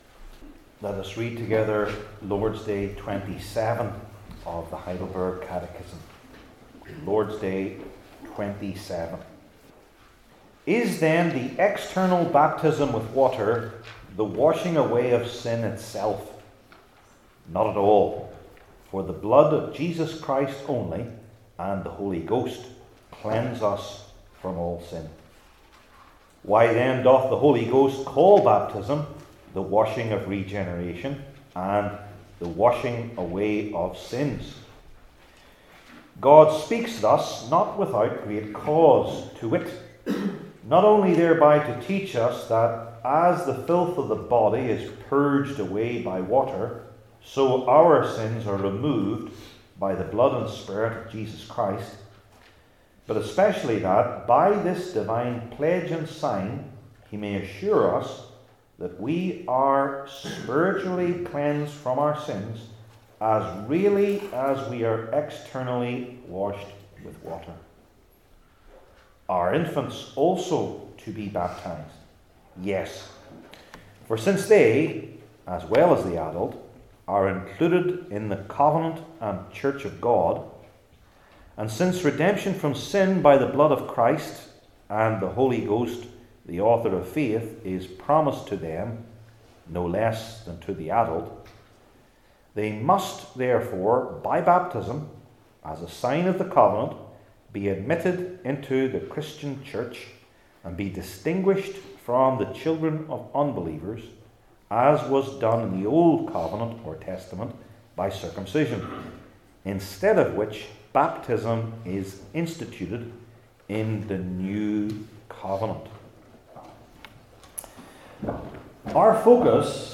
Old Testament Sermon Series I. The Meaning of Little Children II.